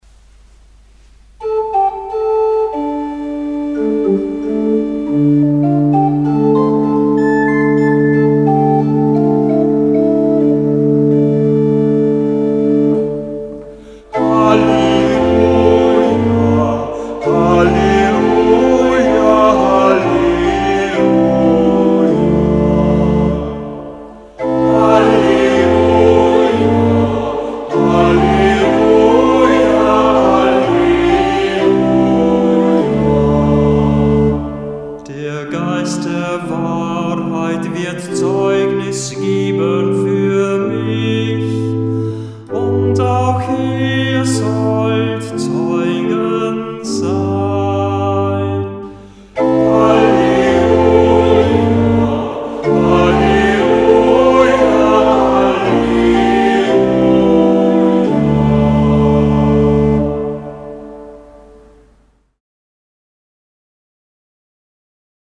Halleluja aus dem Gotteslob